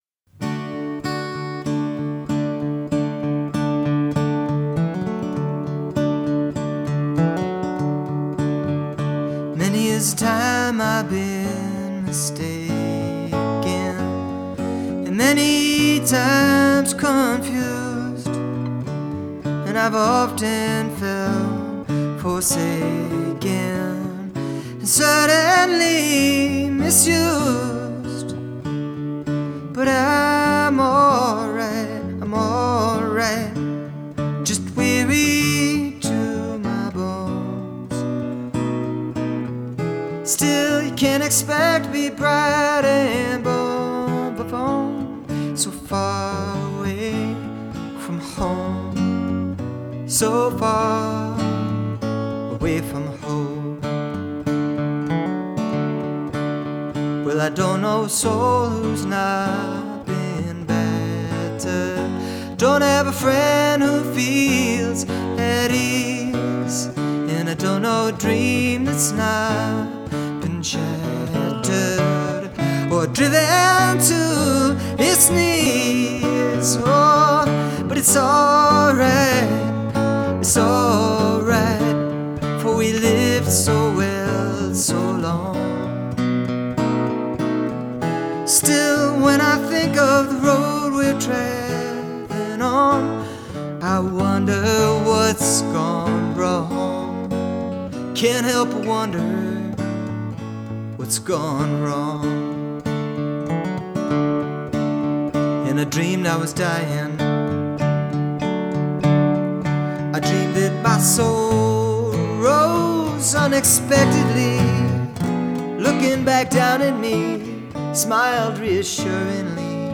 Acoustic Demos